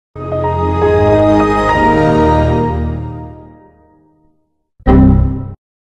MSN Messenger on Windows XP sound effects free download